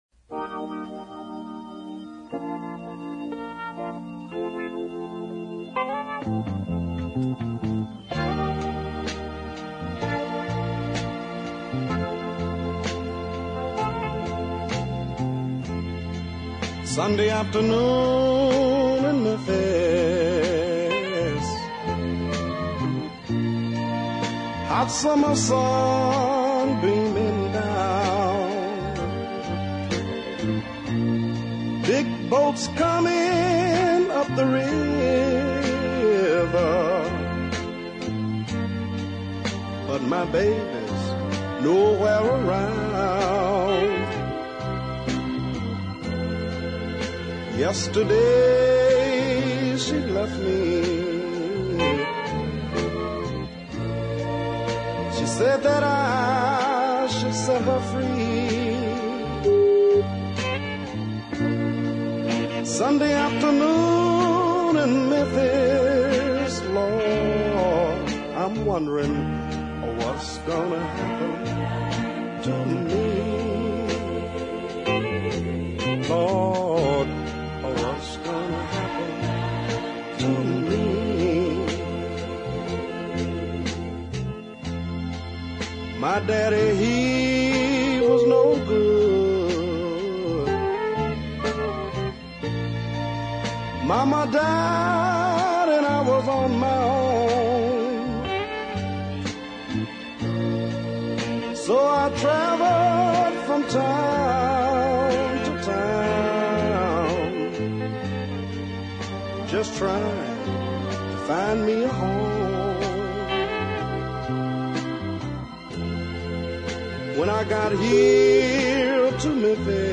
a fine singing talent
gentle, rather meditative